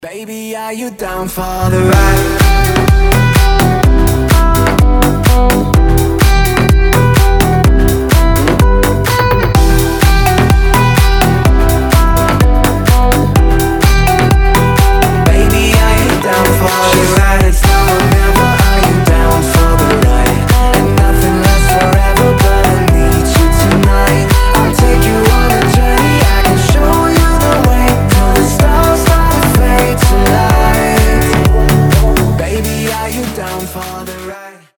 • Качество: 320, Stereo
гитара
мужской голос
мелодичные
house